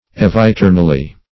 -- Ev`i*ter"nal*ly , adv.